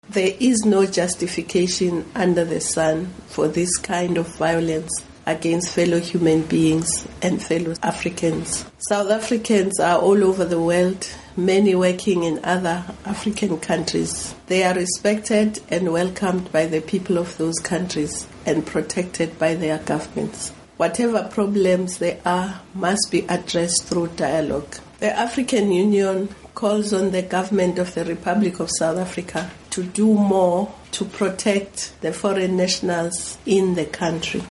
Interview With Nkosazana Dlamini Zuma